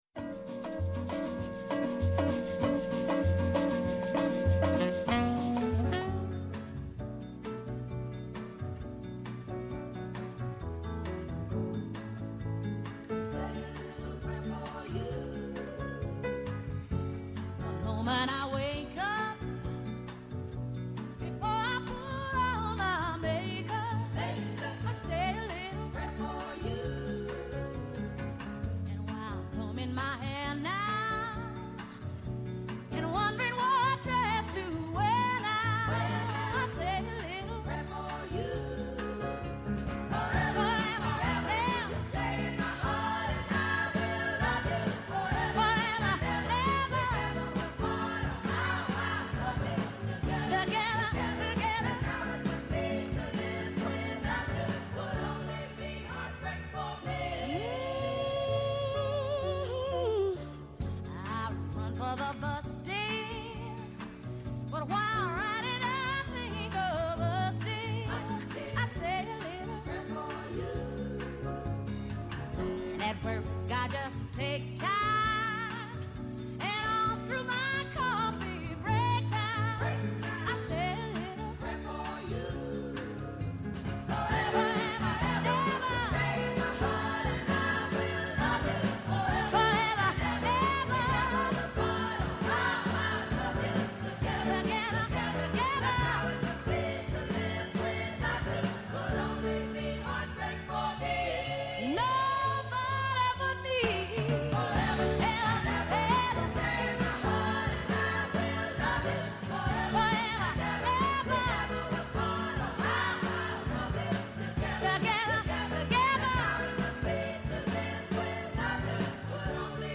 -Ο Χρήστος Τριαντόπουλος, Υφυπουργός Κλιματικής Κρίσης και Πολιτικής Προστασίας